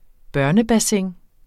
Udtale [ ˈbɶɐ̯nə- ]